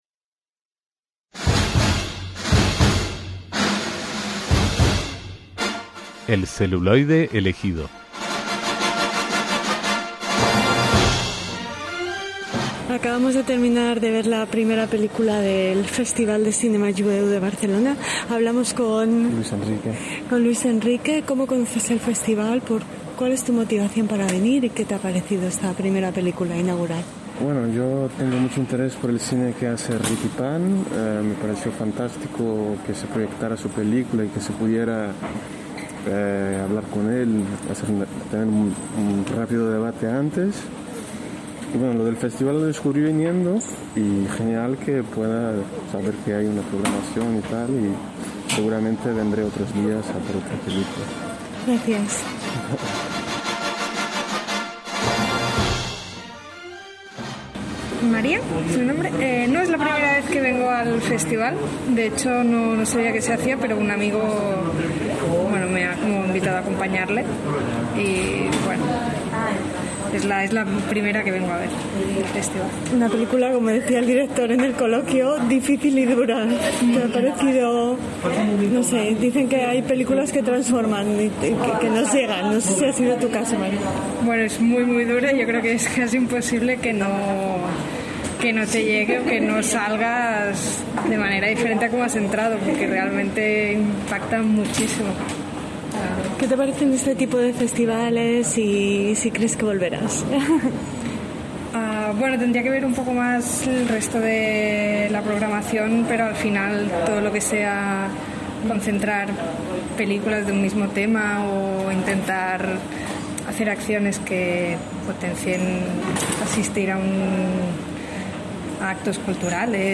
EL CELULOIDE ELEGIDO - Ha comenzado el Festival de Cinema Jueu de Barcelona, y Radio Sefarad ha estado en la sesión inaugural para hablar con los asistentes y protagonistas en la proyección de Irradiés (Irradiats) del documentalista camboyano Rithy Pan.